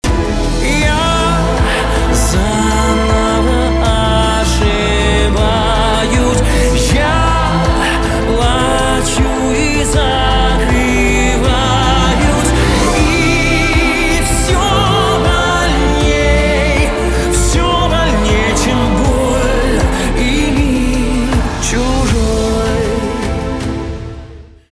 грустные , чувственные
скрипка , барабаны